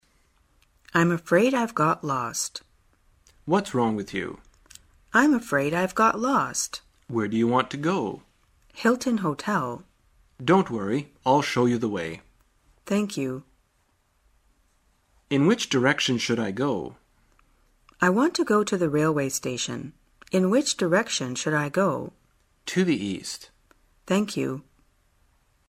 在线英语听力室生活口语天天说 第67期:怎样在迷路时求助的听力文件下载,《生活口语天天说》栏目将日常生活中最常用到的口语句型进行收集和重点讲解。真人发音配字幕帮助英语爱好者们练习听力并进行口语跟读。